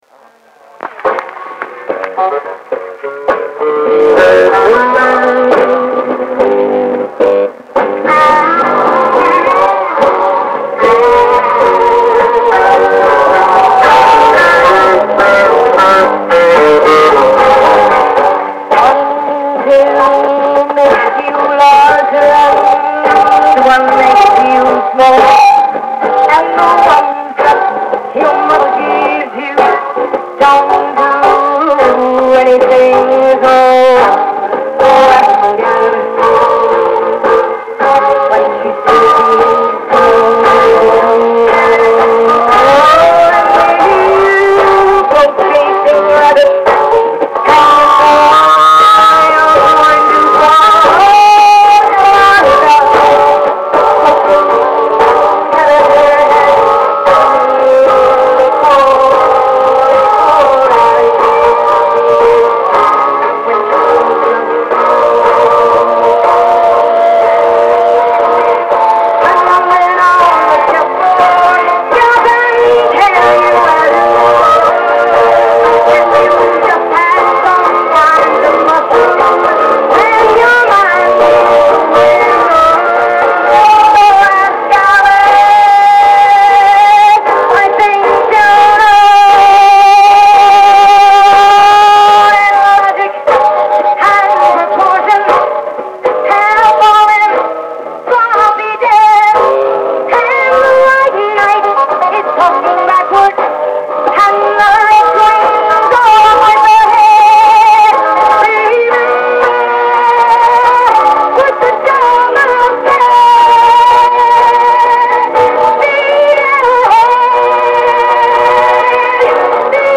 Psychodelic version Actual Woodstock tape
on a reel-to-reel recorder